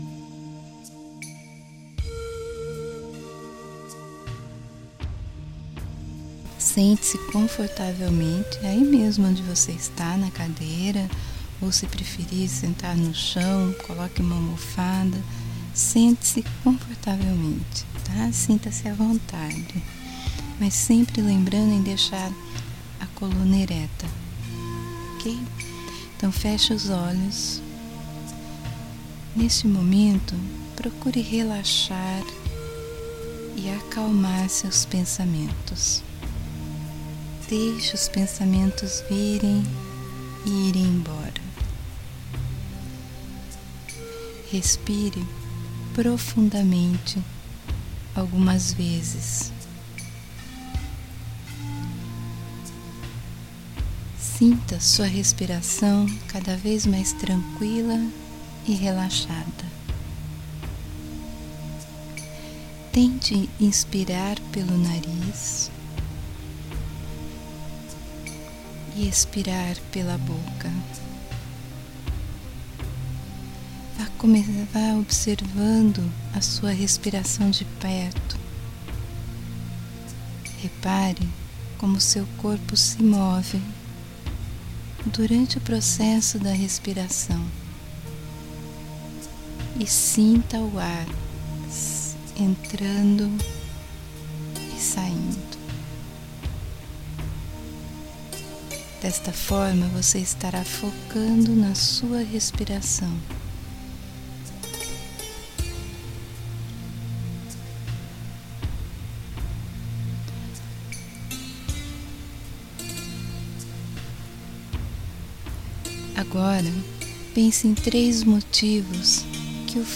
Meditacao_procrastinação.mp3